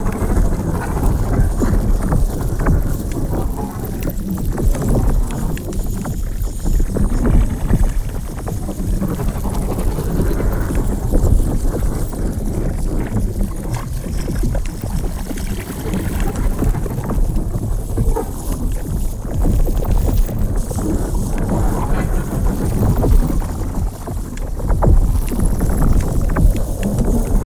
shatterpoint_idle.ogg